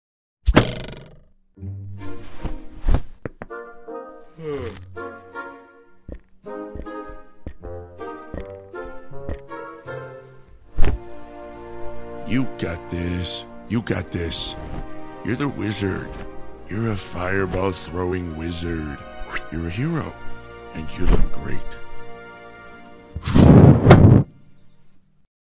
with live sound fx!